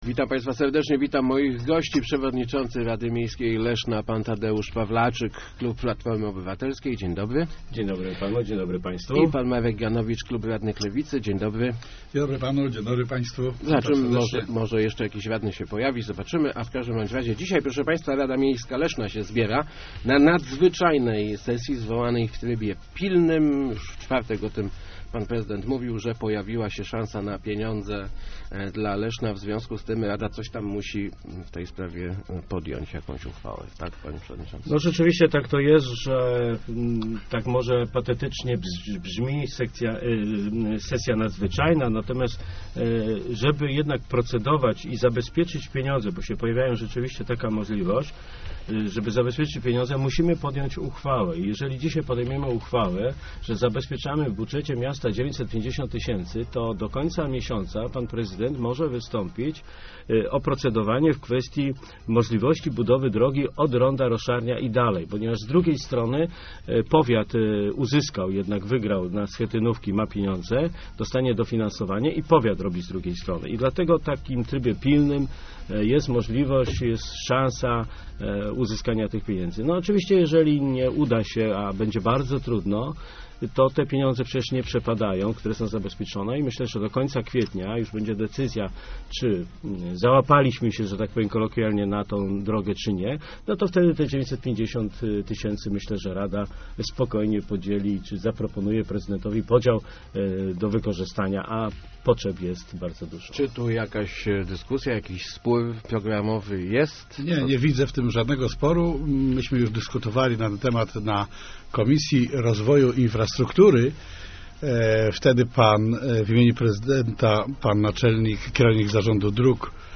Jest pełna zgoda, żeby wystąpić o pieniądze na przebudowę ul. Estkowskiego - mówił w Rozmowach Elki Marek Ganowicz z klubu Lewicy Rady Miejskiej Leszna.